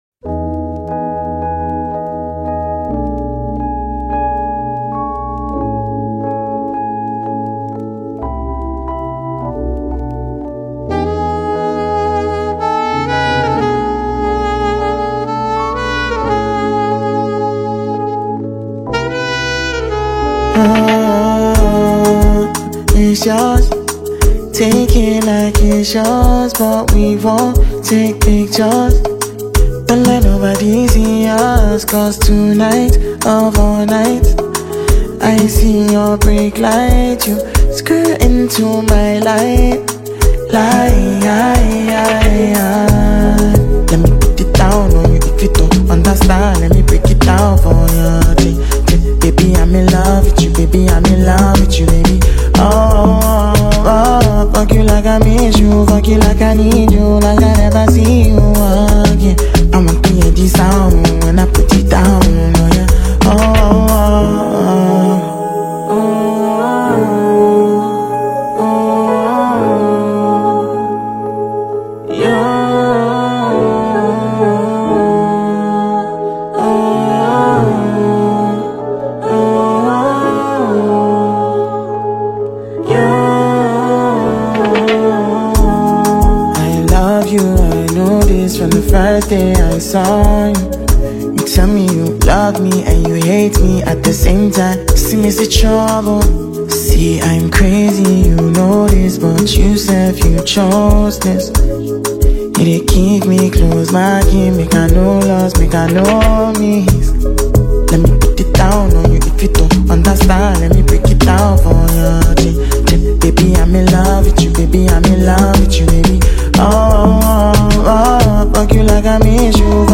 Multi-gifted Nigerian vocalist, and tune writer